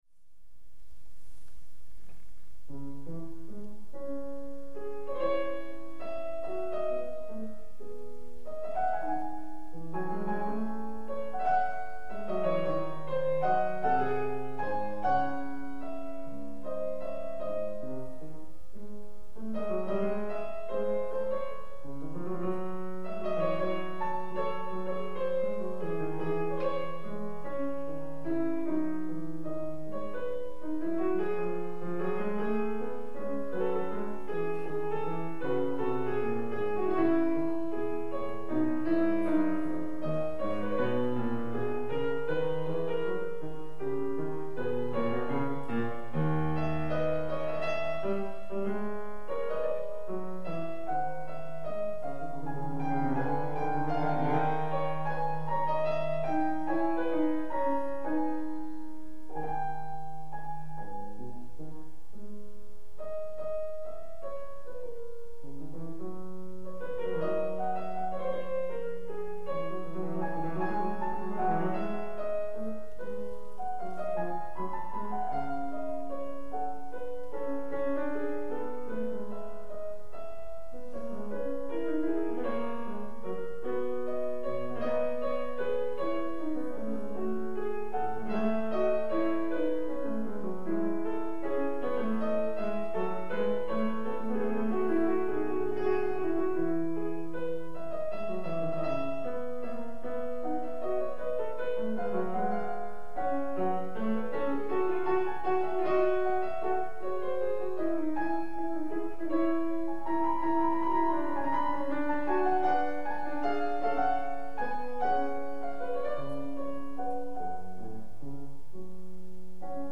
PIANIST & PAINTER
SOLO